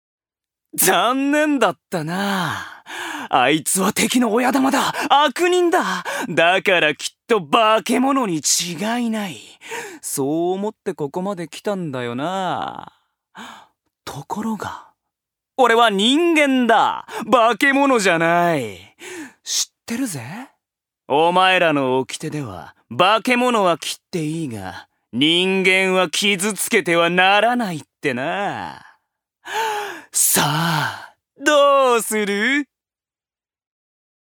所属：男性タレント
音声サンプル
セリフ４